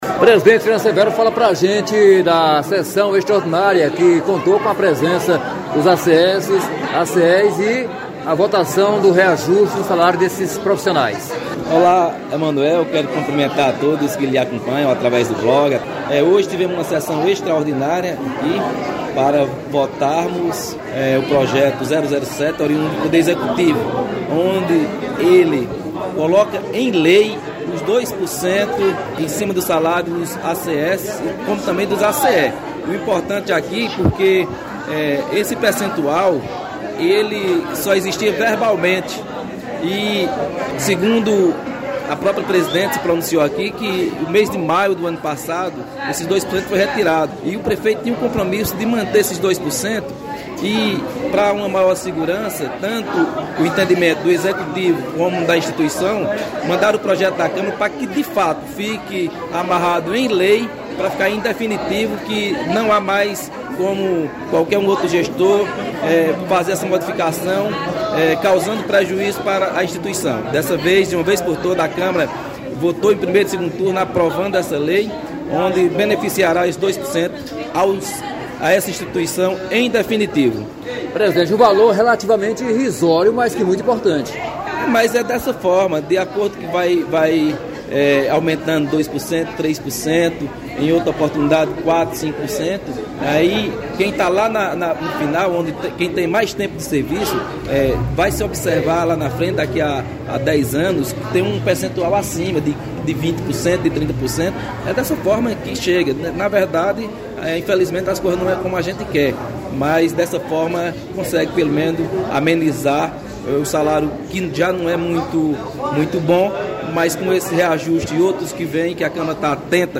Confira a entrevista com o presidente da Câmara.